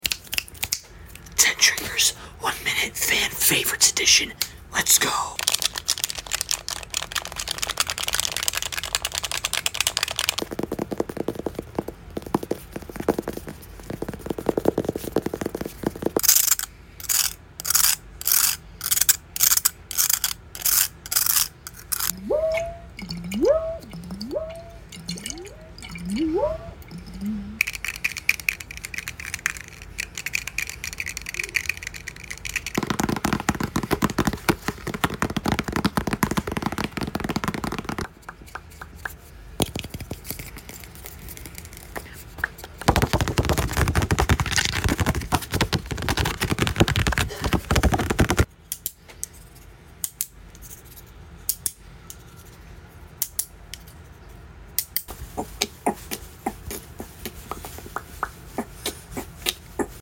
10 TRIGGERS, 1 MINUTE: FAN Sound Effects Free Download